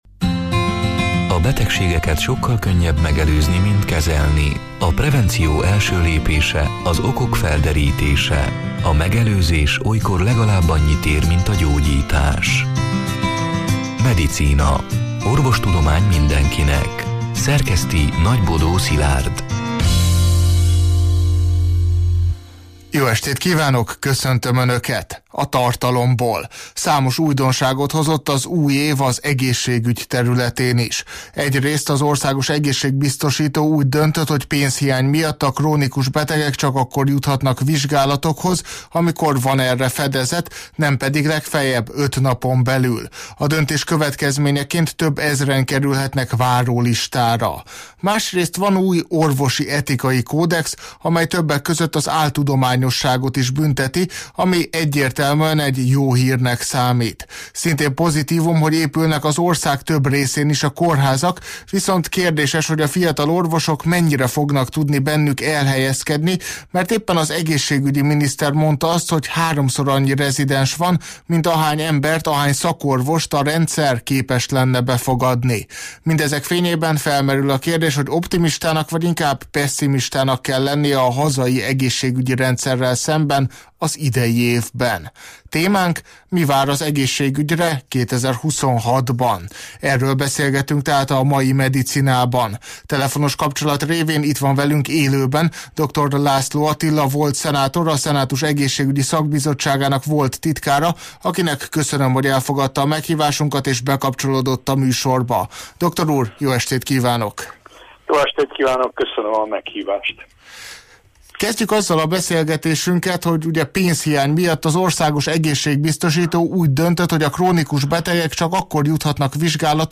A Marosvásárhelyi Rádió Medicina (elhangzott: 2026. január 14-én, szerdán este nyolc órától élőben) c. műsorának hanganyaga: